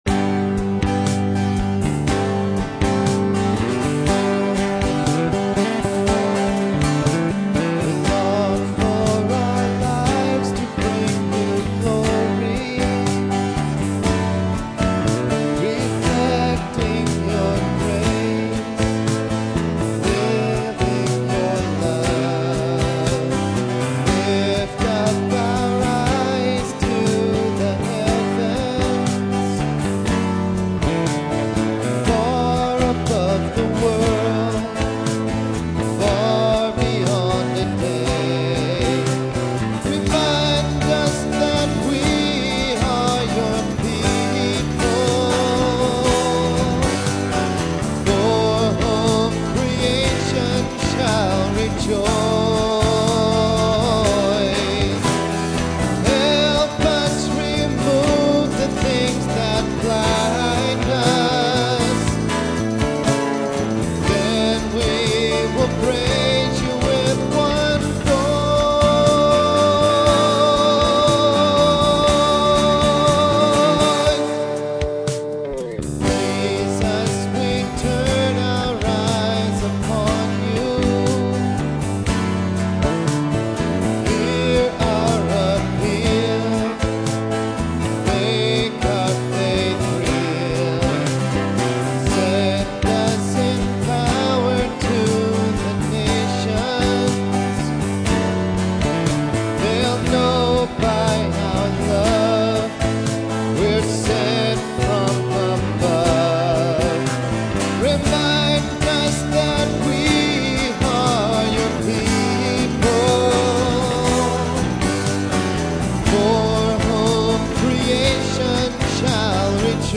This song, my first attempt at worship music, came to me in a dream in the summer of 1996--just after graduating school.